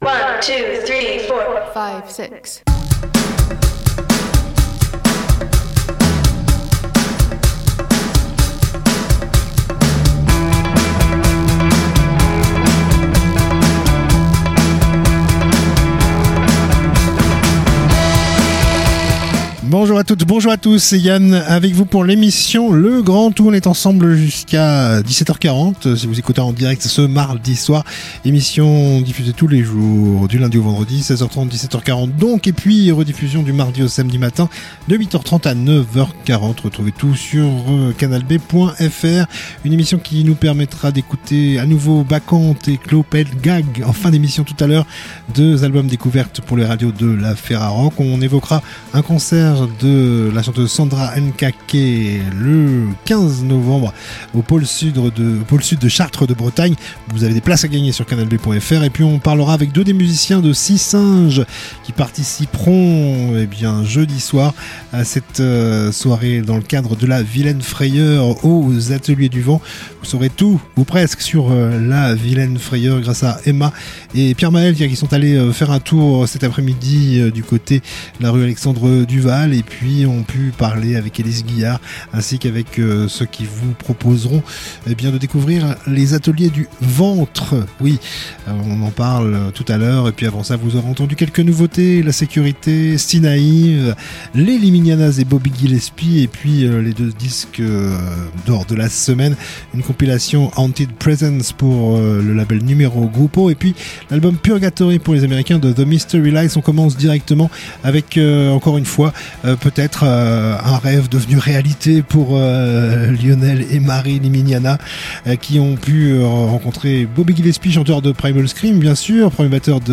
itv musique